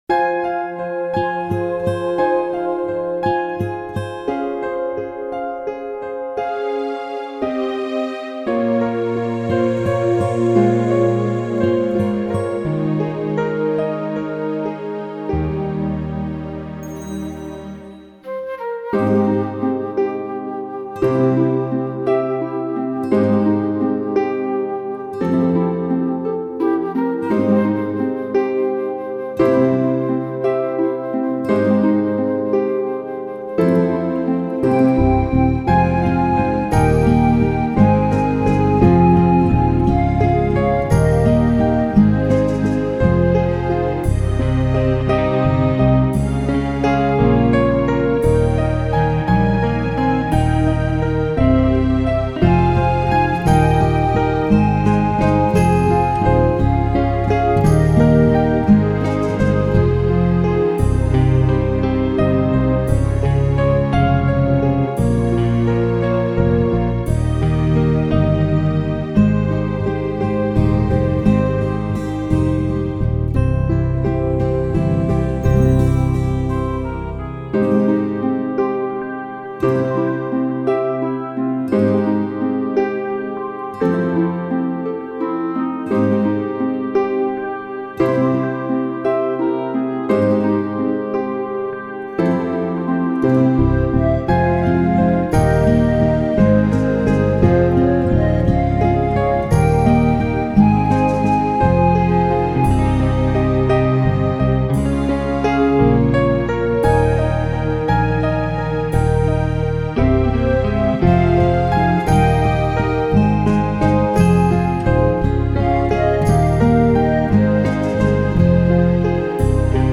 So with eagerness, below is a preliminary recording of “I’ve Always Cared #2” where my vocal is unfinished.
acoustic guitar
electric guitar
I’ve Always Cared Home Recording 4-2-17